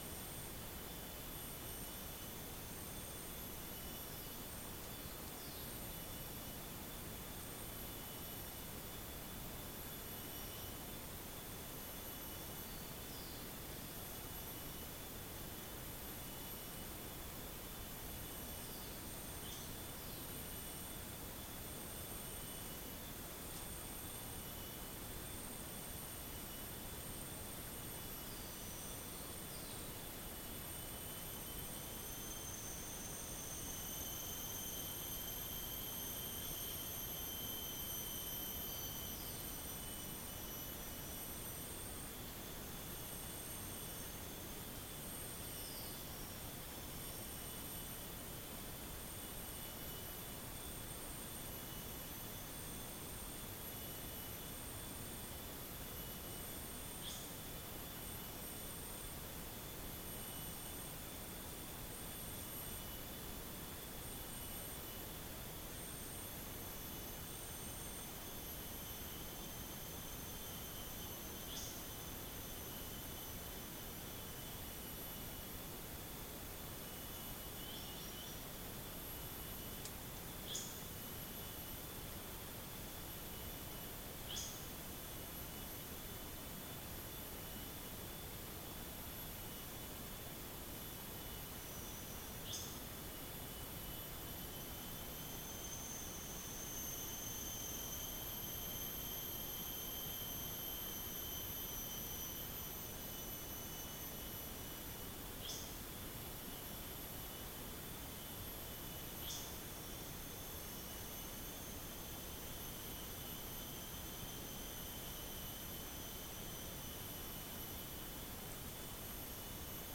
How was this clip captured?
Demonstration soundscapes